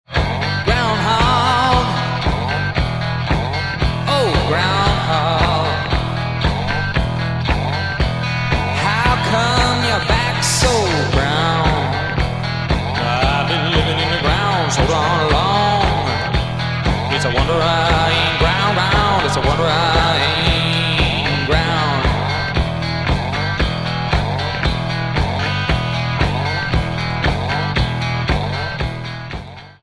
bass, vocals
drums, percussion
Album Notes: Recorded at Can-Base Studios, Vancouver, Canada